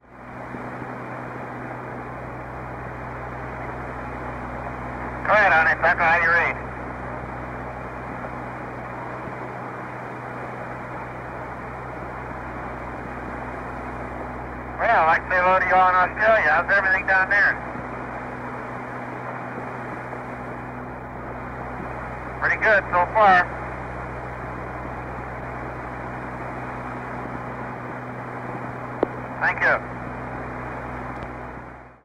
Audio recorded at Honeysuckle Creek
Downlink audio only.